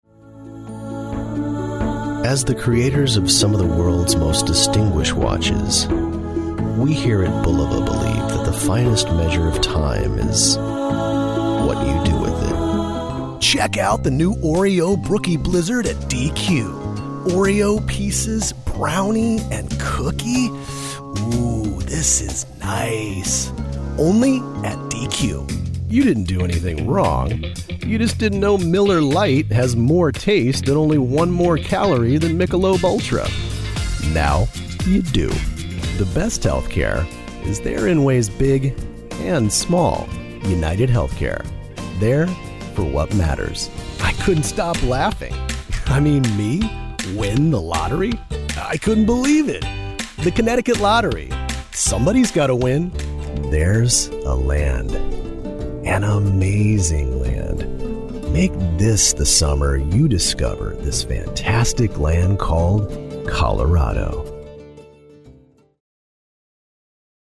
Extremely versatile voice, age range 20-55.
Impressive 3-octave range.